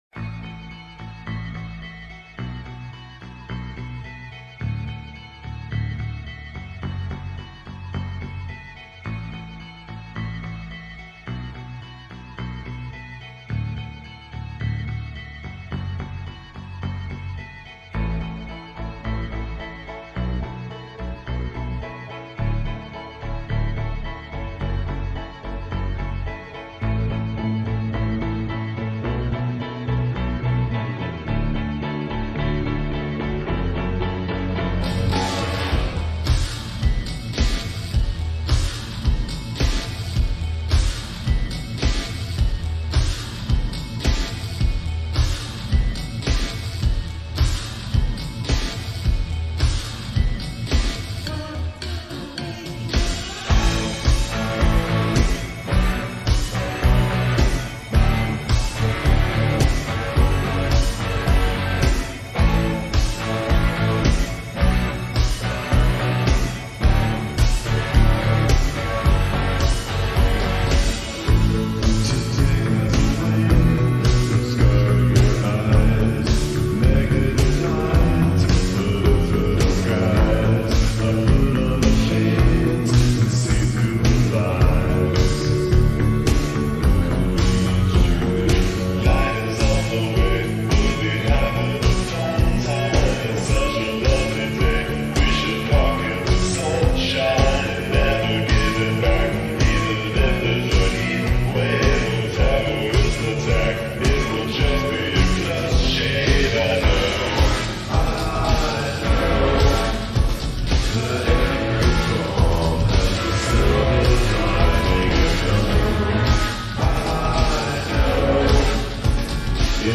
Зарубежная